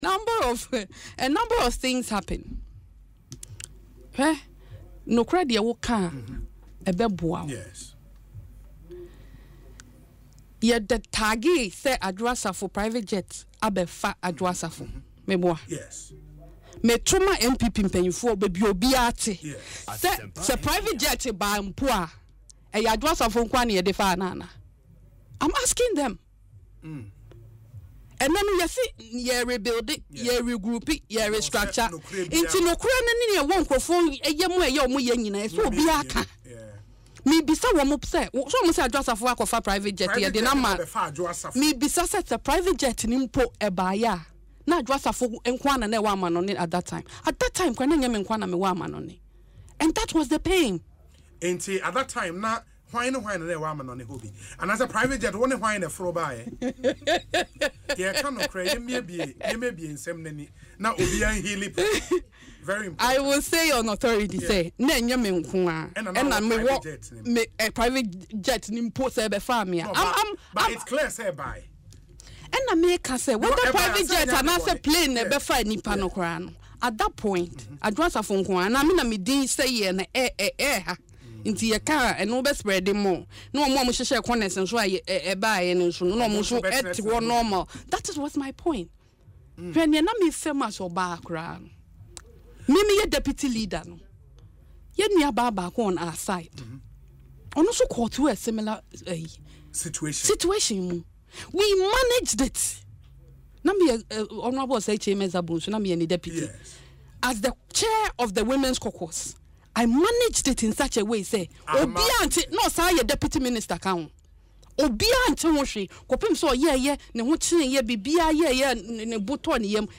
In an interview on Asempa FM’s Ekosii Sen, the former Gender Minister neither confirmed nor denied the claim.